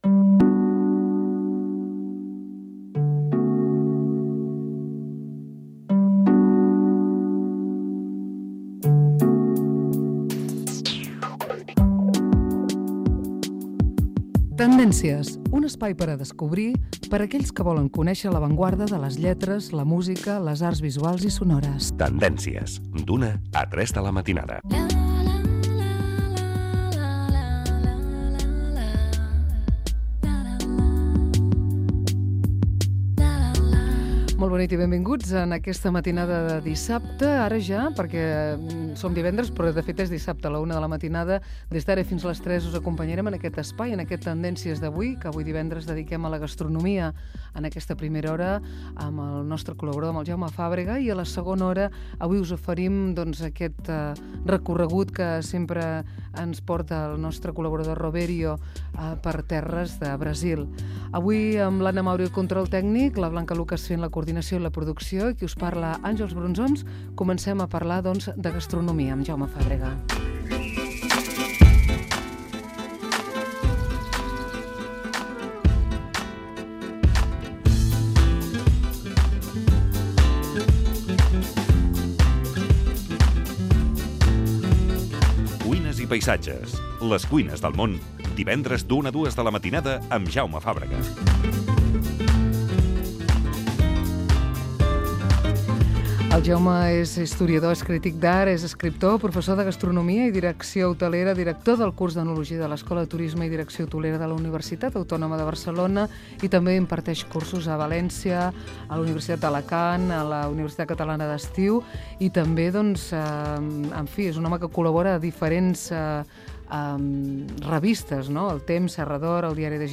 Cultura
FM